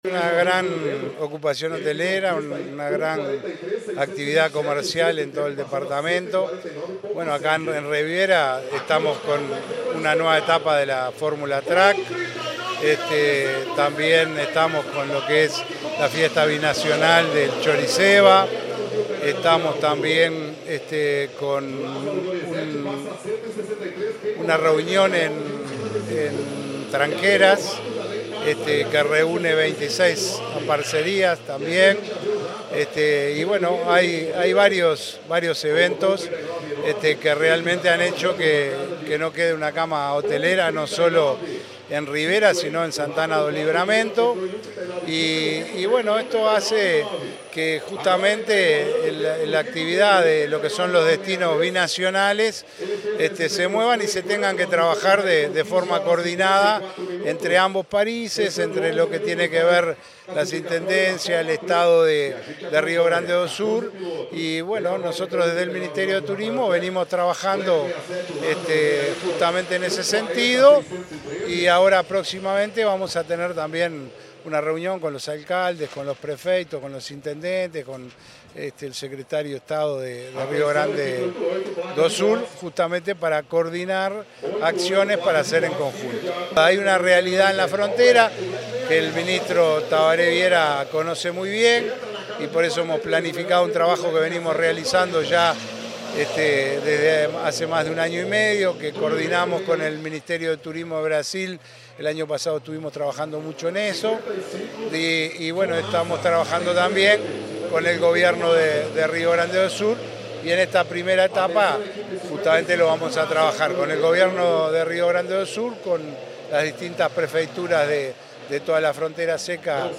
Palabras del director nacional de Turismo, Roque Baudean
En el autódromo internacional de Rivera, se inauguró el pasado fin de semana un área de boxes, en el marco de la segunda fecha de la Fórmula Truck 2023 brasileña. El Ministerio de Turismo aportó 250.000 dólares para las obras, que posicionan al autódromo como referente en la región. El director nacional de Turismo, Roque Baudean, participó en el evento.